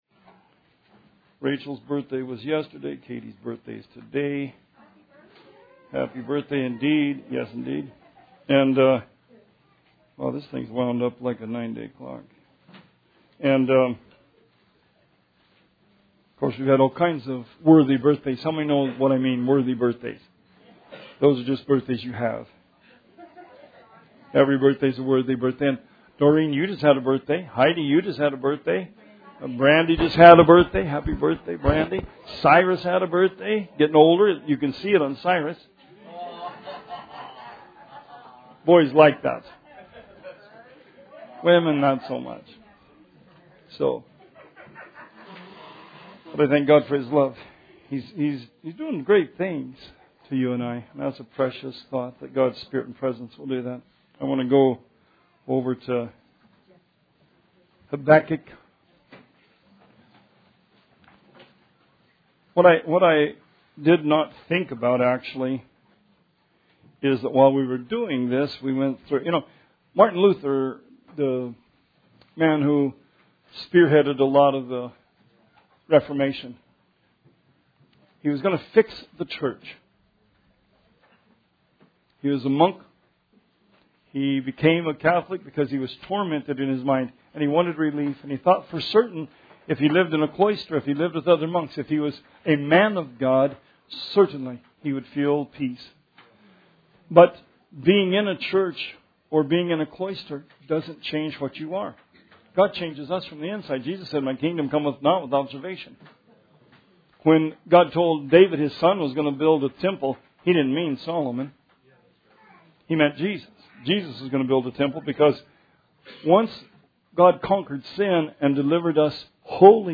Sermon 11/4/17